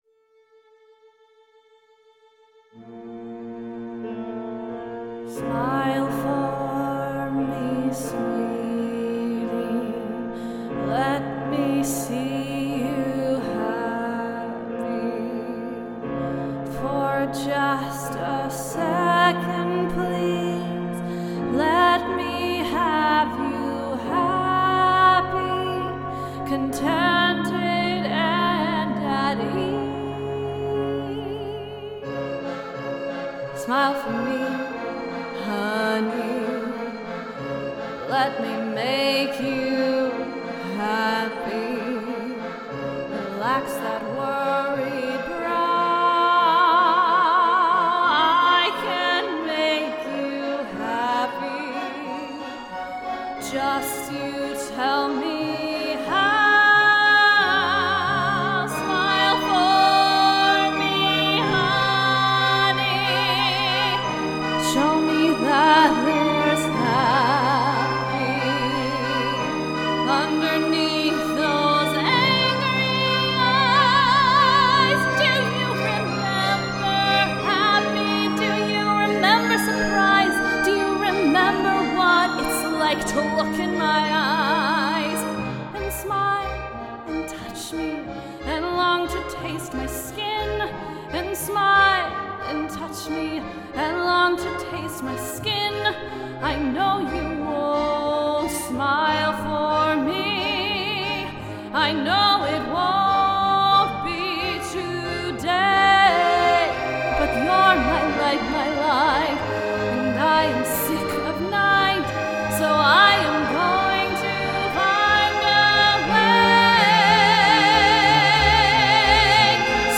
Workshop at HERE ARTS